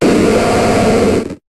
Cri d'Ursaring dans Pokémon HOME.